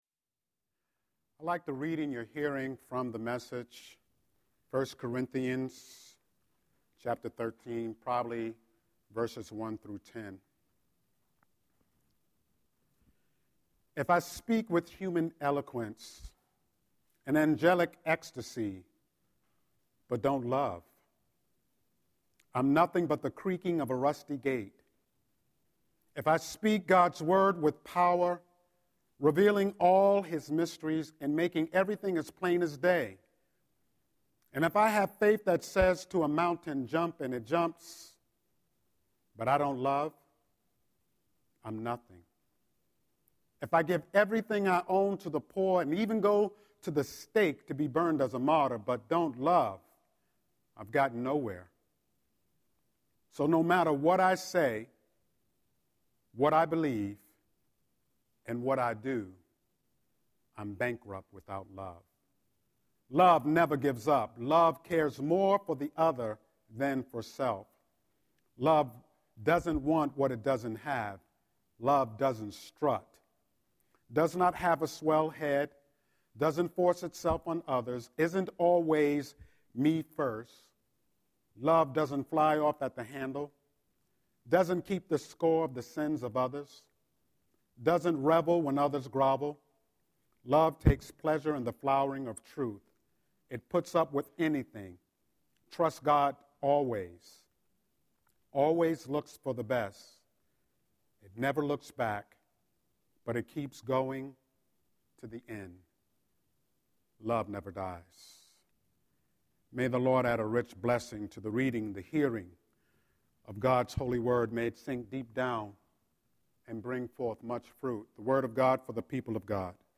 07-12-Scripture-and-Sermon.mp3